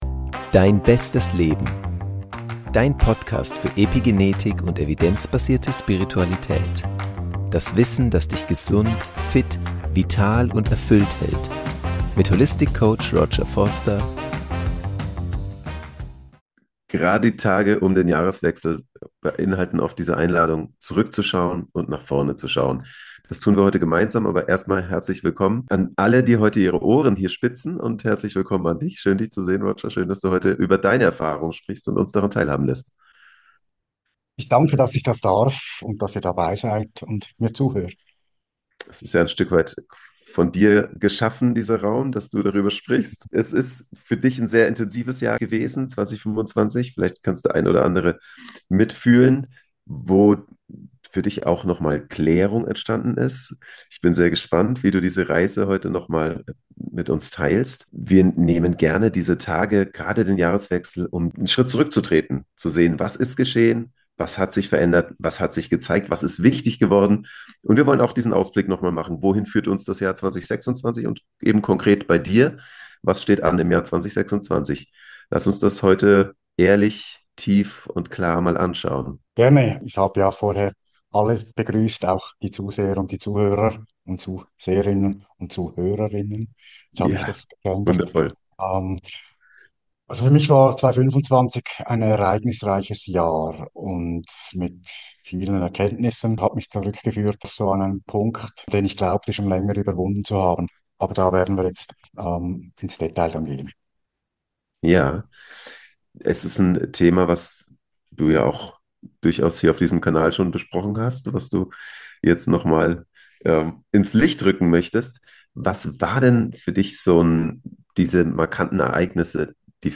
Beschreibung vor 3 Monaten Rückblick 2025 & Ausblick 2026 | Ein Gespräch über Klarheit und Richtung Was war.